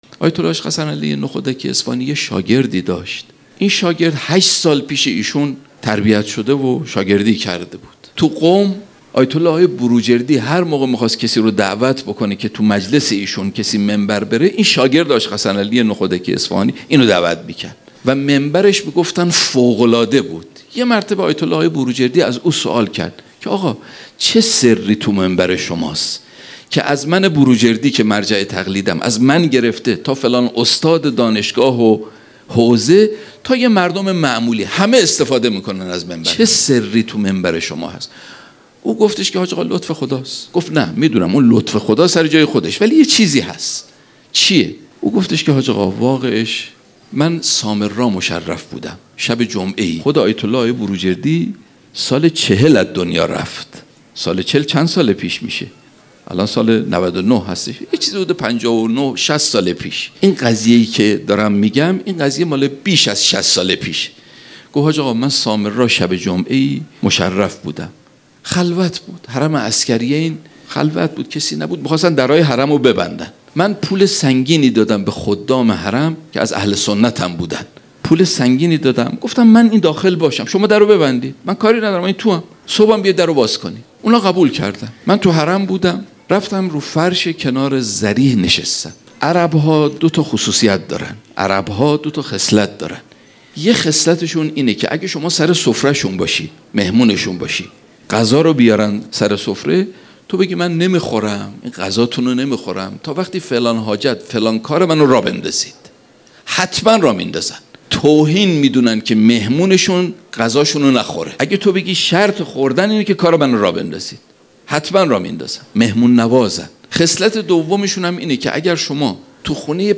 هیئت شاهزاده علی اصغر کاشان | ایام فاطمیه